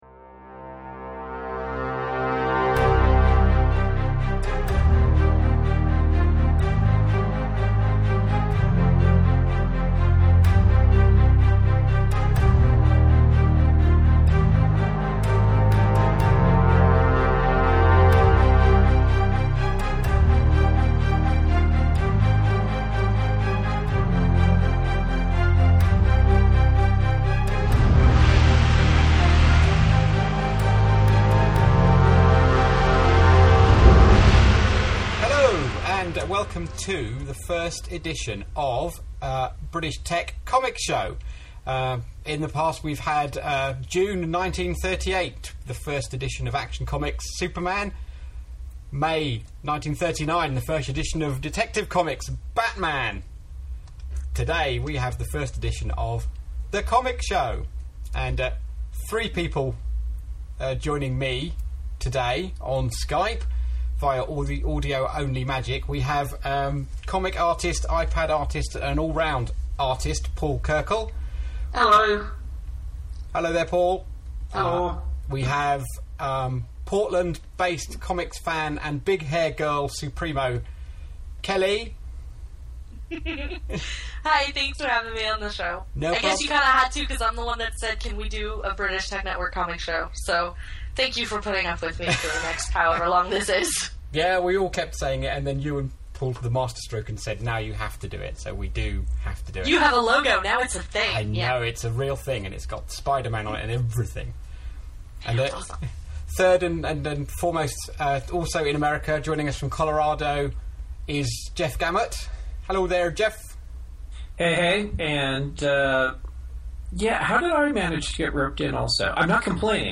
So teething-trouble is what the 'T' stands for in BTN and we have some issues getting the whole thing started this week and the quality of Audio BUT...dont judge on this episode alone